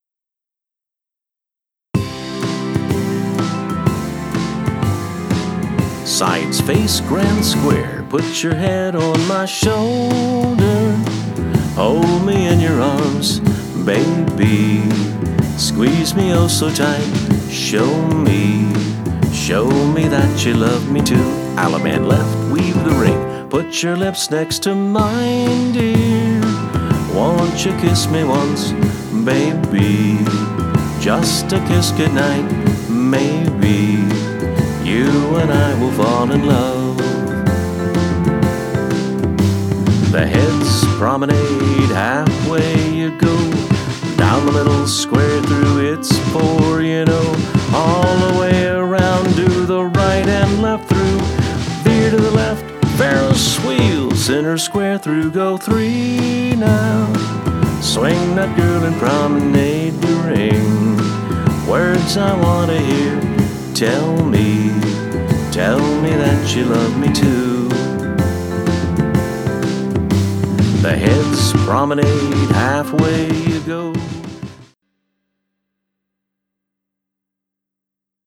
Instrumental Instrumental (No Melody)